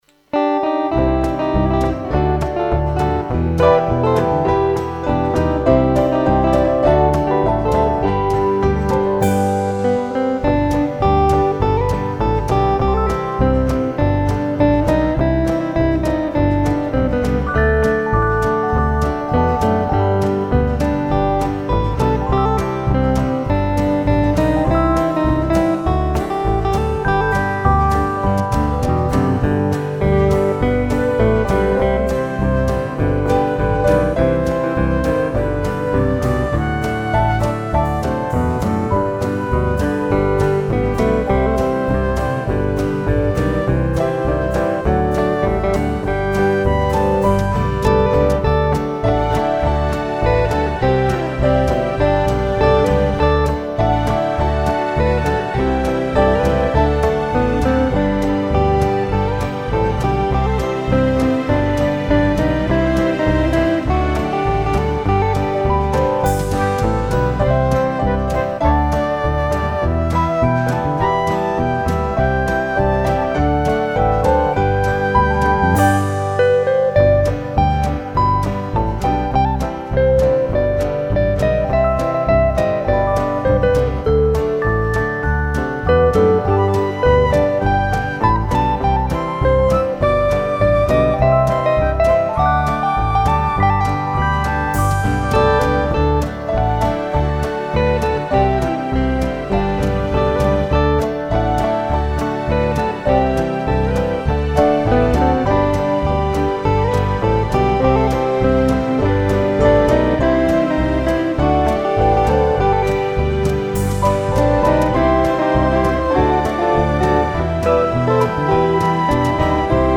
This new release offers a perfect holiday melody
guitar